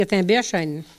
Elle provient de Bois-de-Céné.
Catégorie Locution ( parler, expression, langue,... )